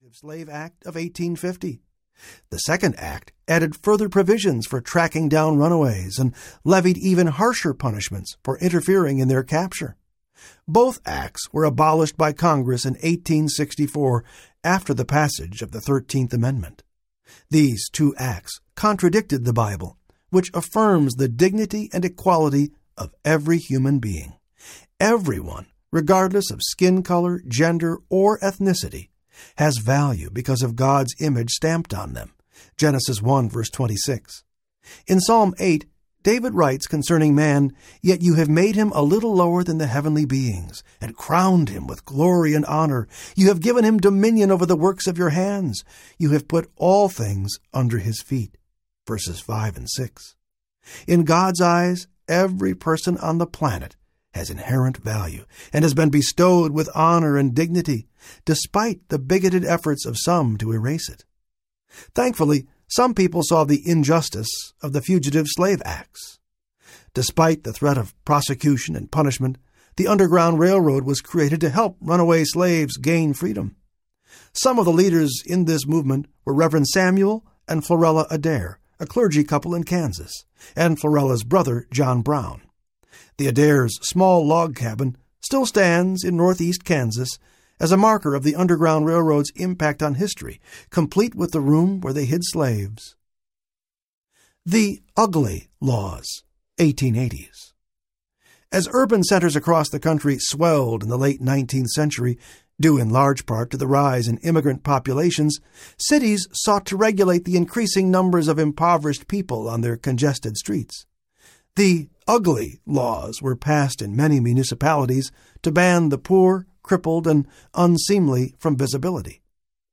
Is Justice Possible? Audiobook
3.87 Hrs. – Unabridged